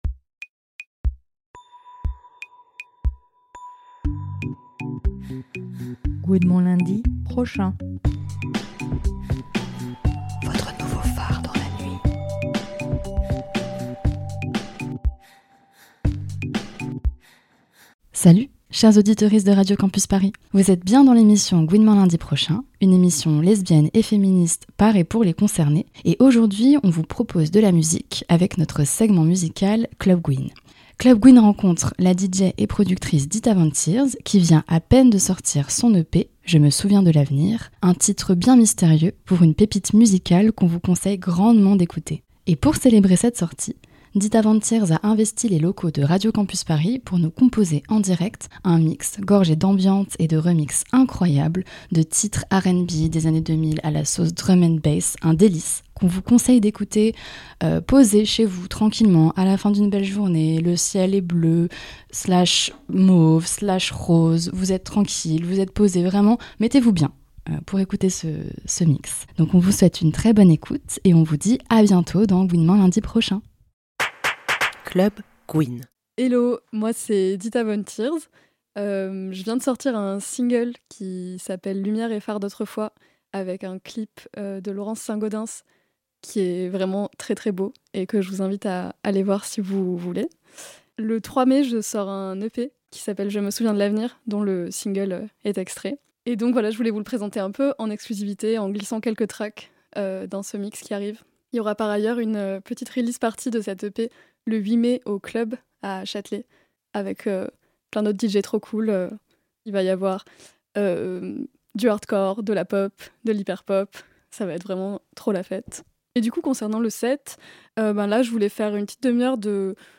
Productrice et DJ